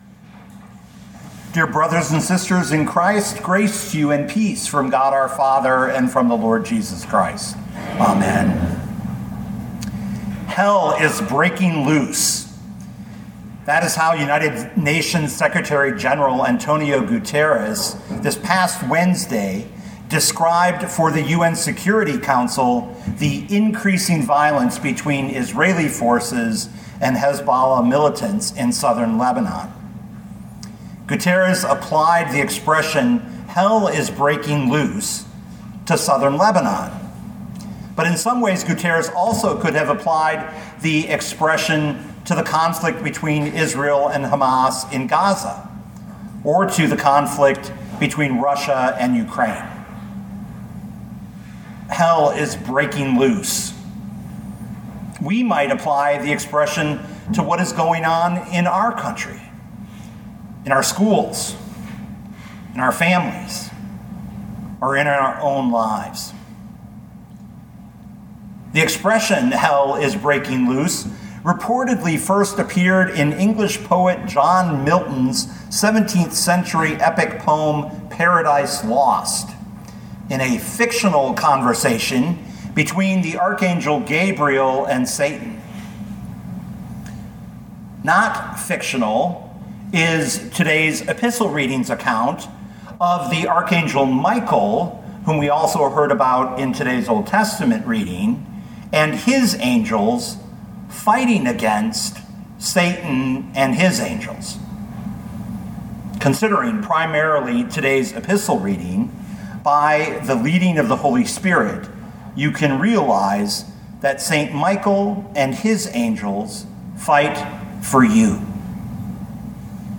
2024 Rev 12:7-12 Listen to the sermon with the player below, or, download the audio.